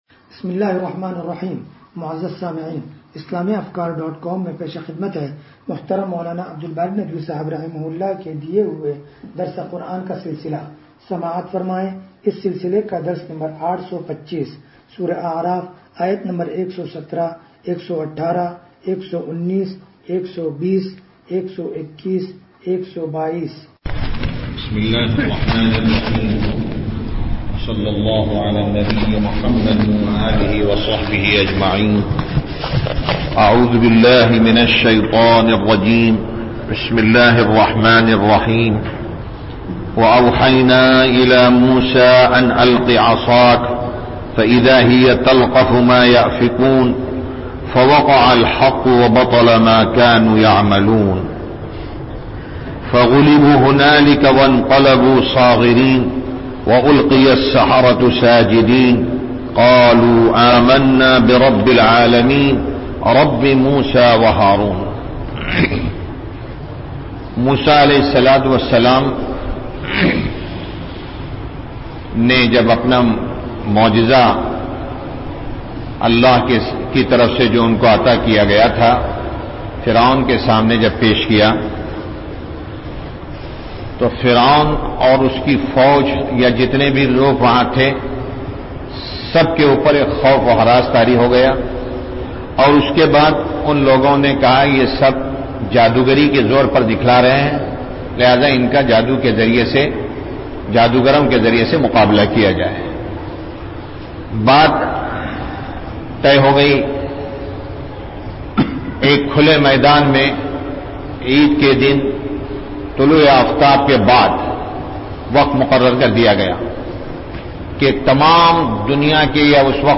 درس قرآن نمبر 0825
درس-قرآن-نمبر-0825.mp3